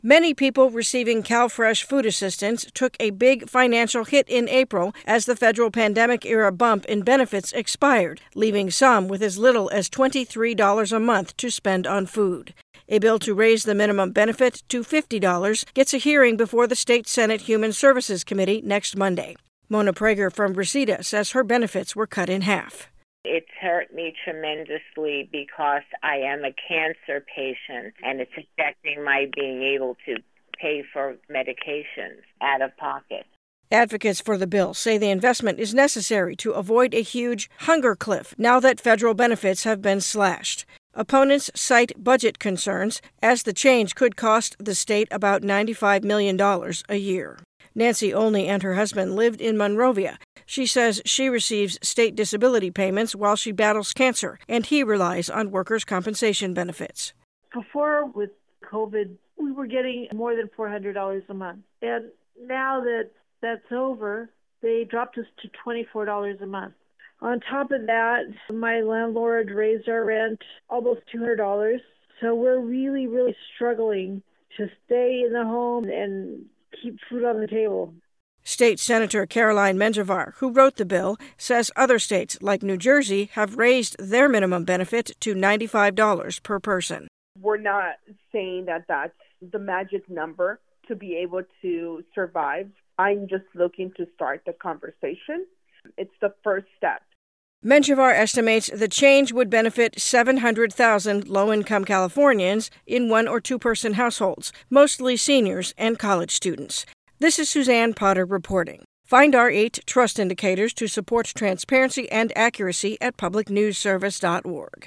Voiceovers